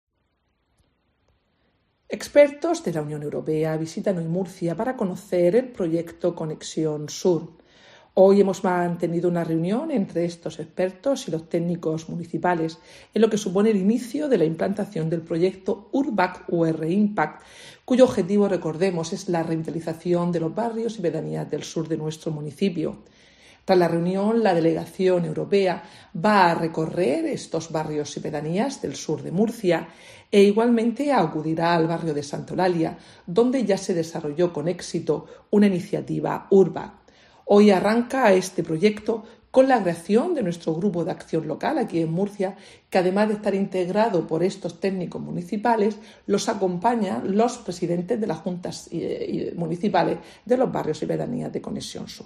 Mercedes Bernabé, concejala de Gobierno Abierto, Promoción Económica y Empleo